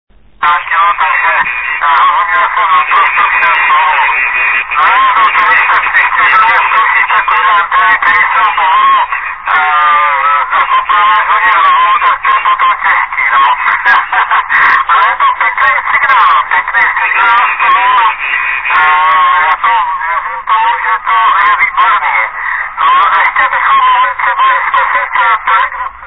Zařízení Traper o výkonu asi 10W.
Bohužel jsem měl sebou jen jednoduchý digitální záznamník Přesto z nahrávky lze zjistit, že spojení nemělo vadu: